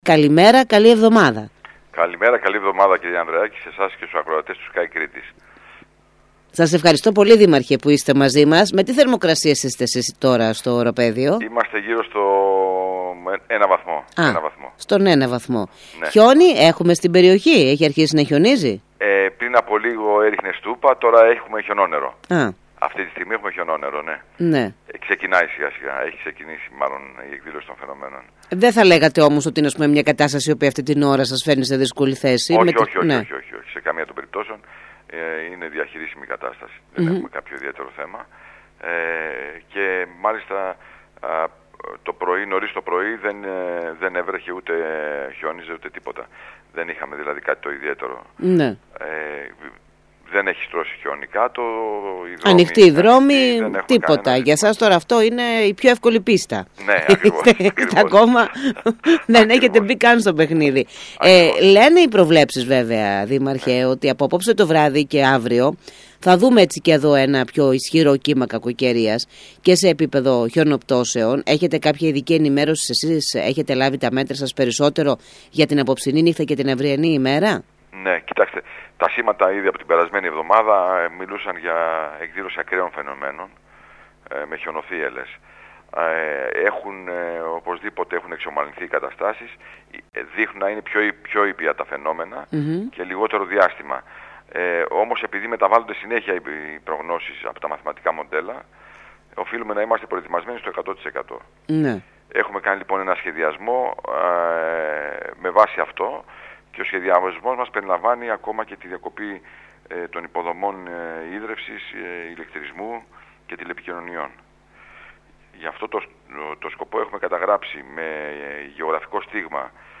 δήλωσε στον Σκάι Κρήτης 92,1